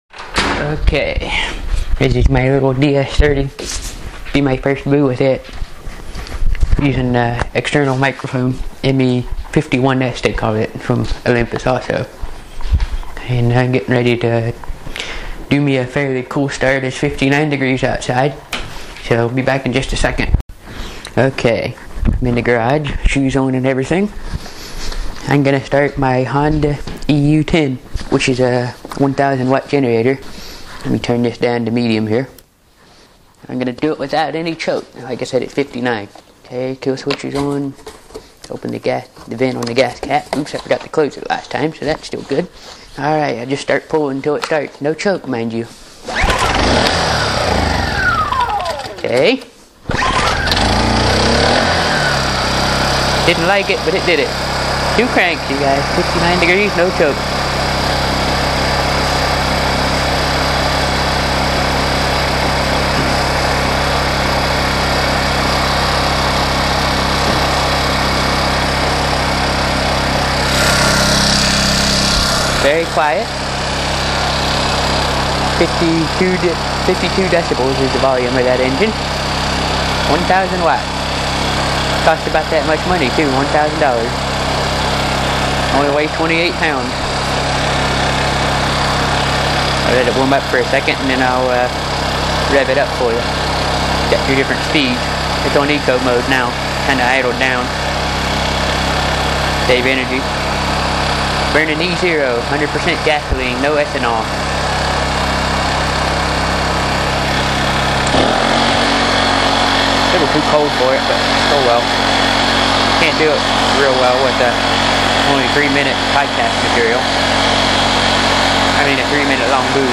Honda EU10 1000wat generator cool start
This is a 28 pound generator that costs as many dollars as it has in wats. It uses a small four stroke 50 some cc engine and it is an inverter generator with eco mode. Idles down when not being used.